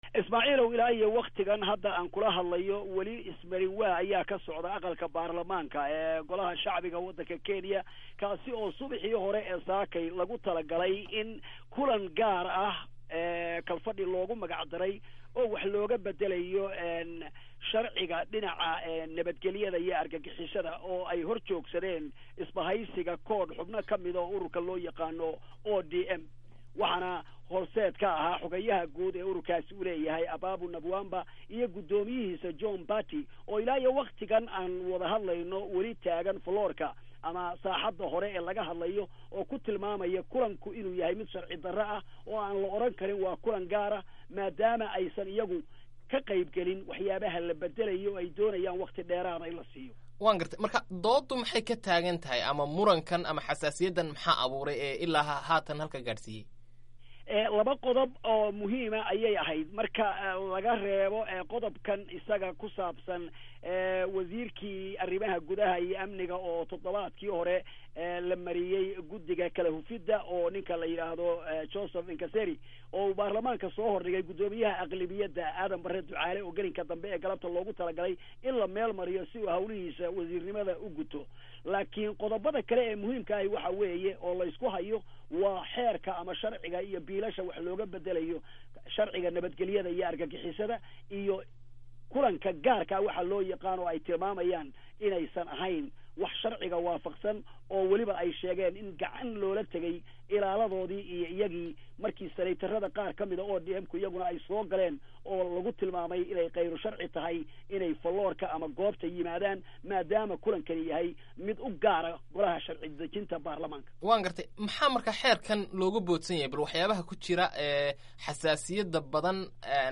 Waraysiga Xaaladda Baarlamaanka Kenya